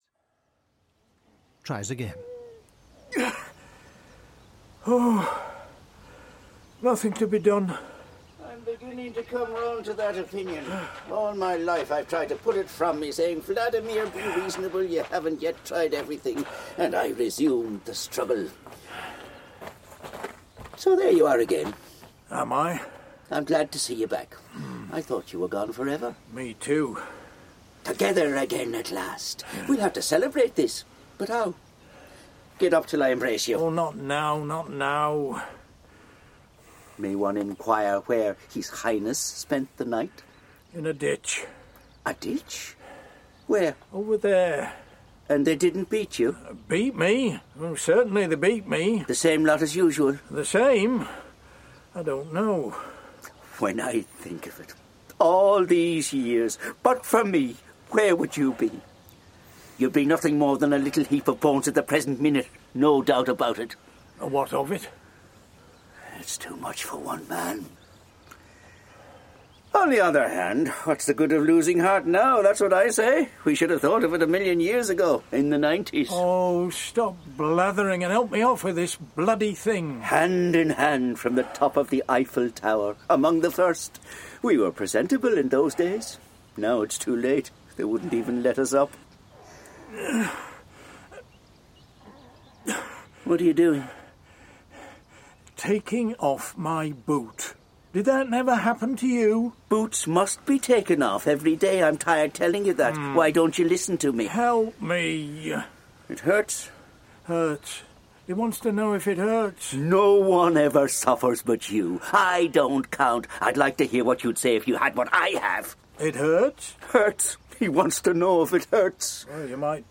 Waiting for Godot (EN) audiokniha
Ukázka z knihy